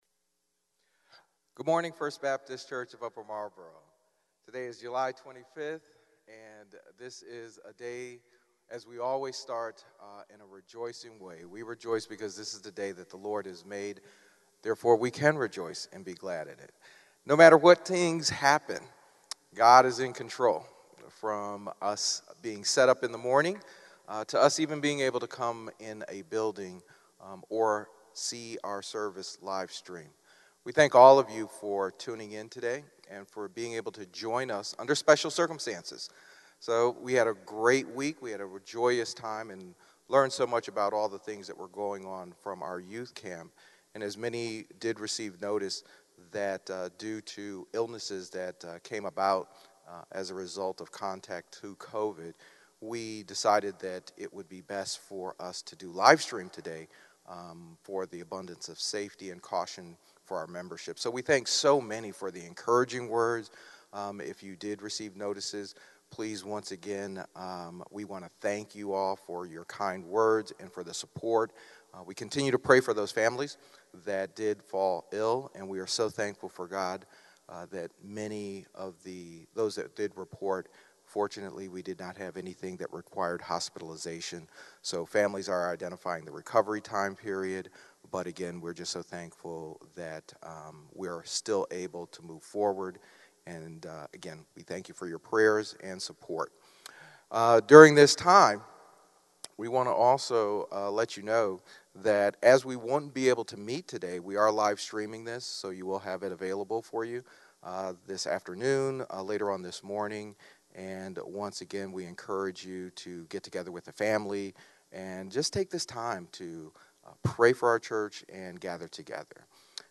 A message from the series "Behold our God!."